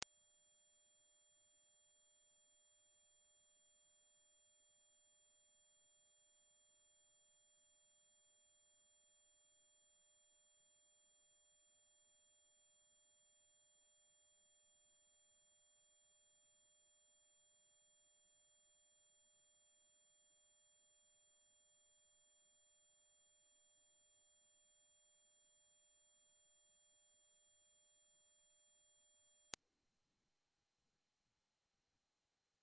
Auch diese Dateien wurden alle als WAV-Dateien aufgezeichnet, nur die Datenmengen und der Zweck haben mich dazu bewogen alle WAV-Dateien in MP3-Dateien zu wandeln.
Sinustöne
Sinus-15000-Hz.mp3